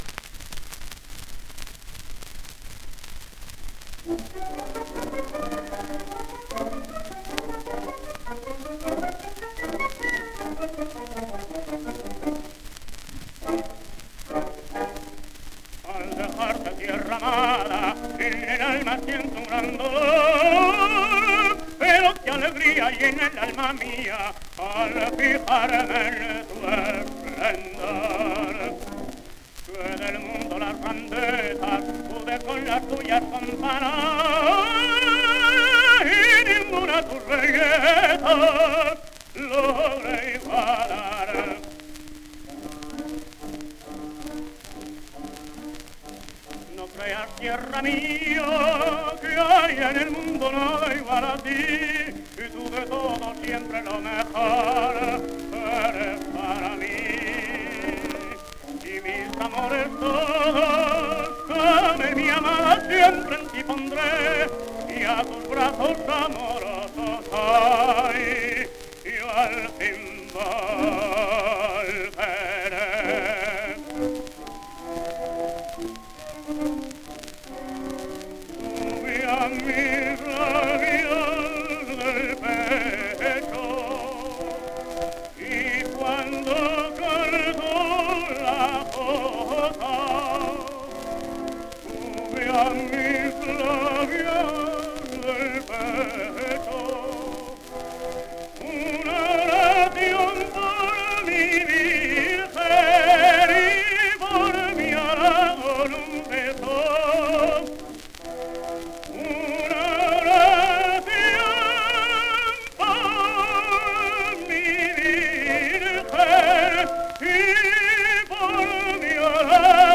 1 disco : 78 rpm